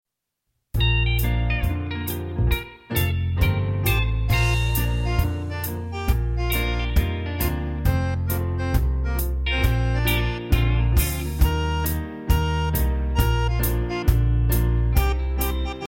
*  Catchy melodies, dumb jokes, interesting stories